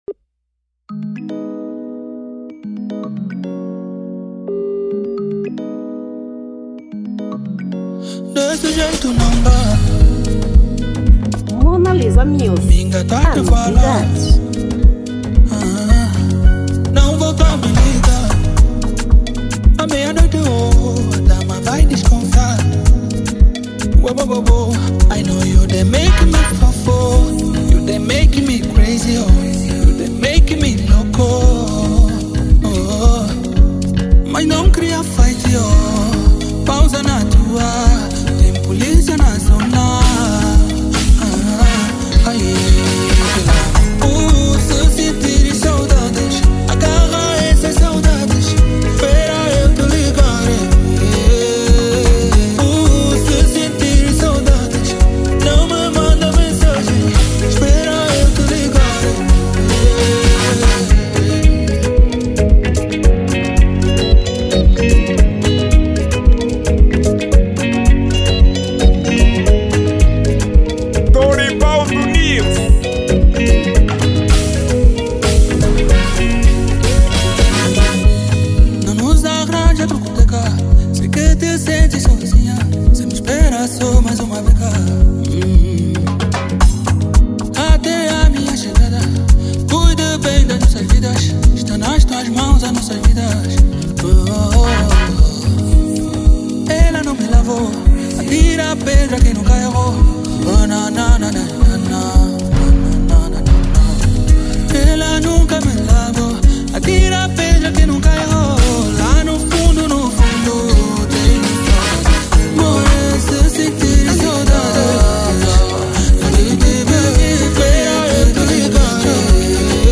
Gênero : Zouk